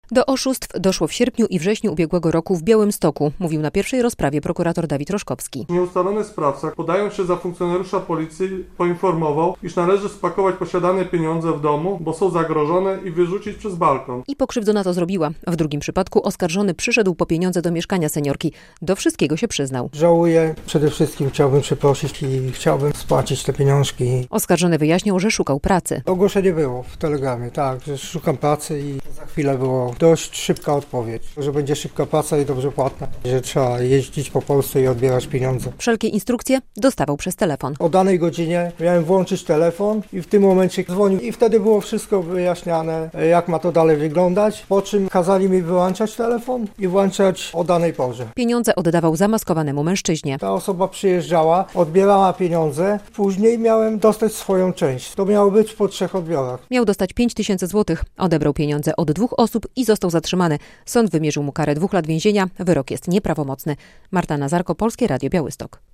Wyrok dla tzw. odbieraka - relacja